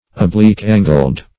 \Ob*lique"-an`gled\
oblique-angled.mp3